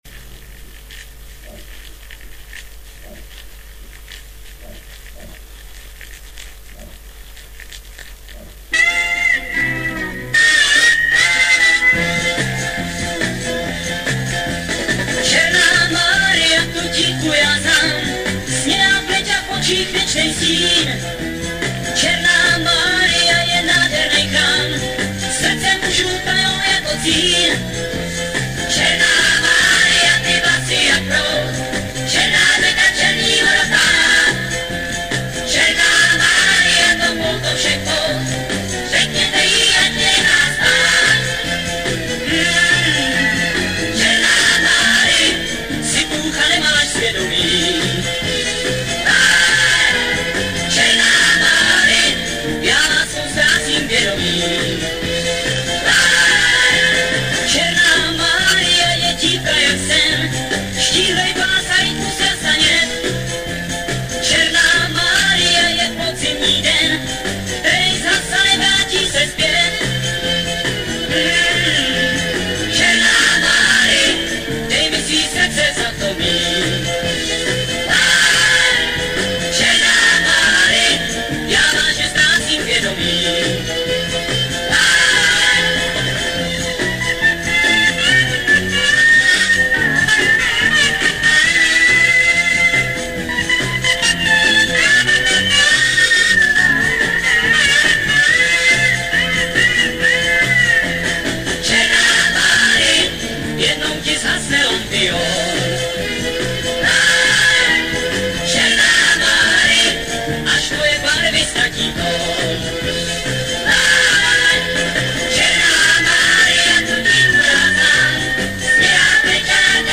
Format: Vinyl, 7", 33 ⅓ RPM, EP, Mono
Genre: Rock, Pop
Style: Pop Rock, Schlager